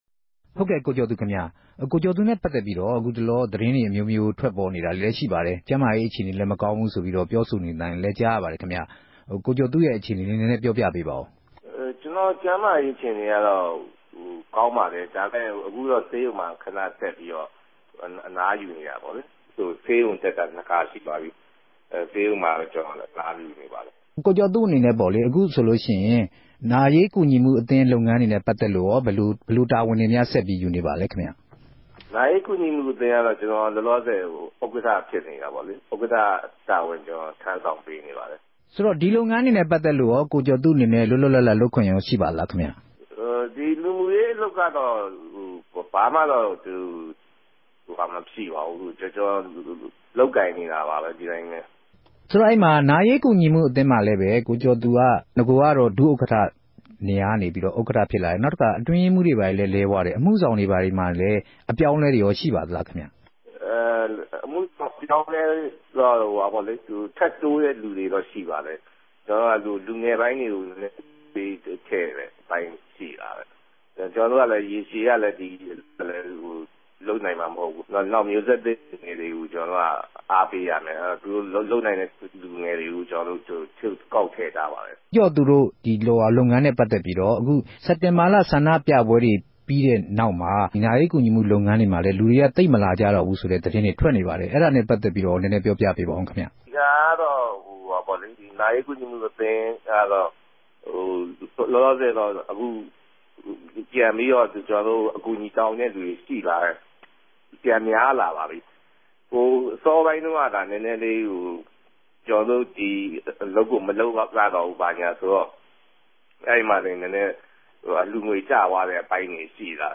ဆက်သြယ်မေးူမန်းတာကို နားဆငိံိုင်ပၝတယ်။